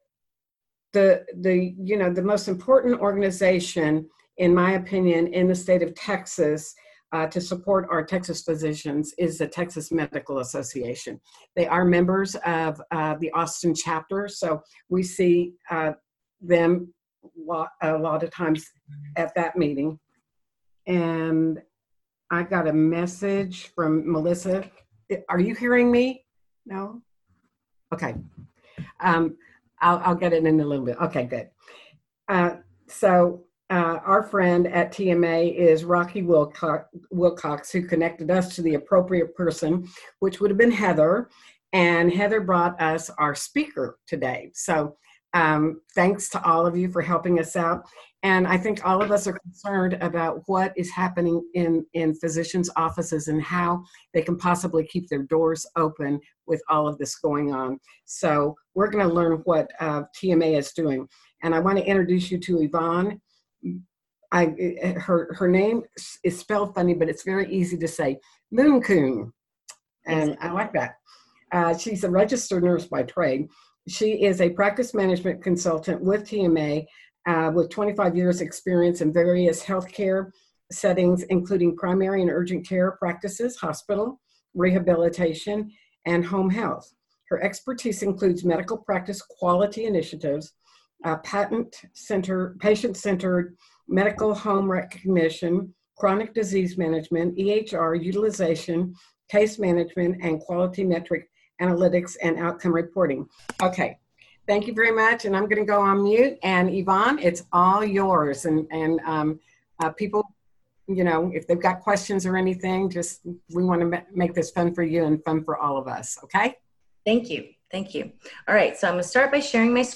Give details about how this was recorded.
Online Conference with Texas Medical Association